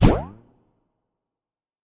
cube_set.mp3